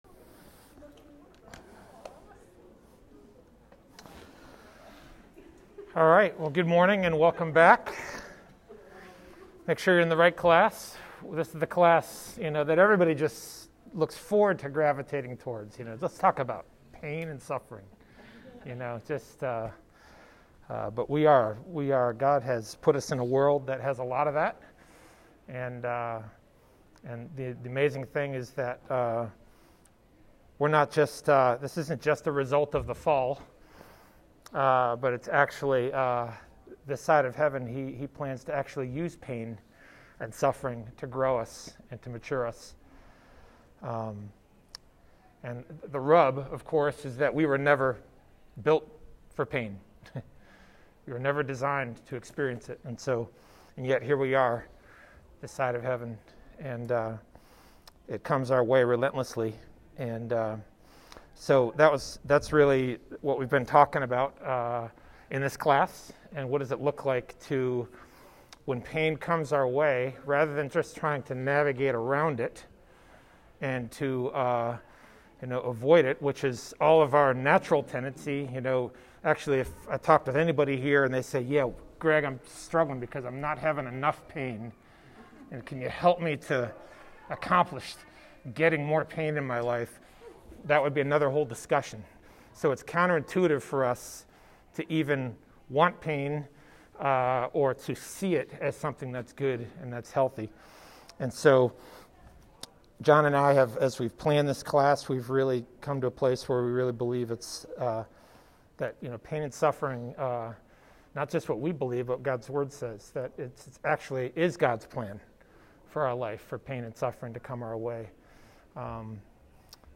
Sunday School Classes